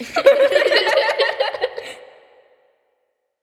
Metro Laughter 1.wav